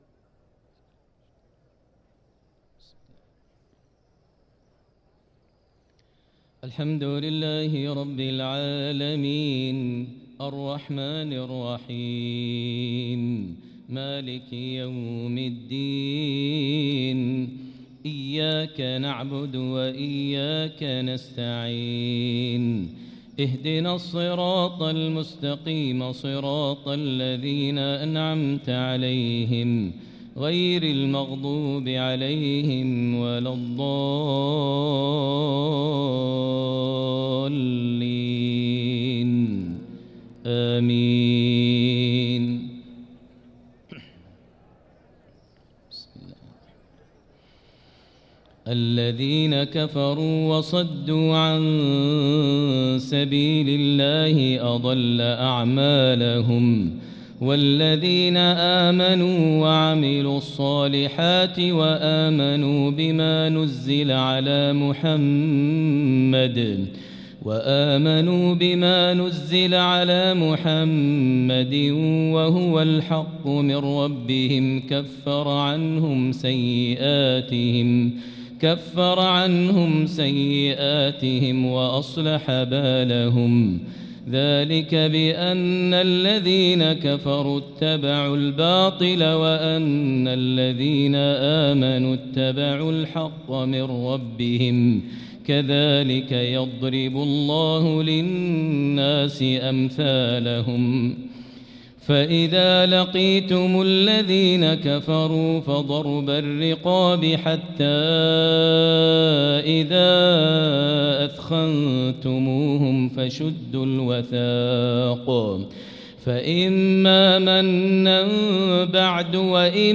صلاة الفجر للقارئ ماهر المعيقلي 22 رجب 1445 هـ